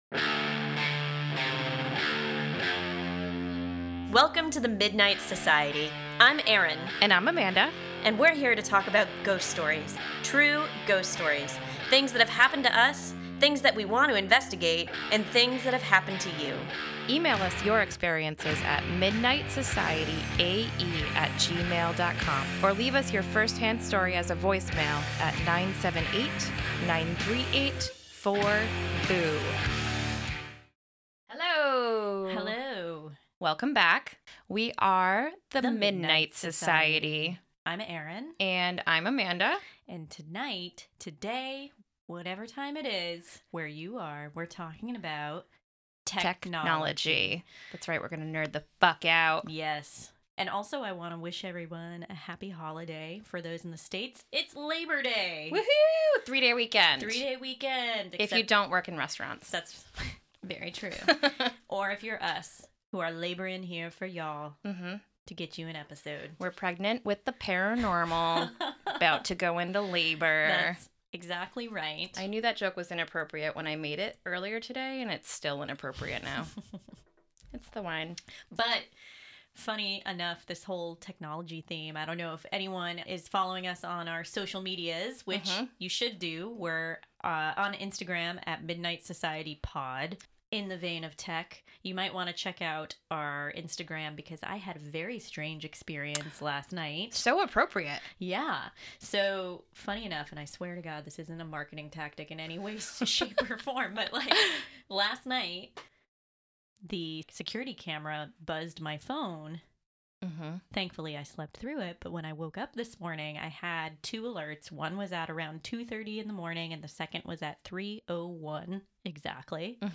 Could spirits be using technology to contact us? This month we hear 911 calls from an abandoned house, as well as tales and tips from an EVP expert.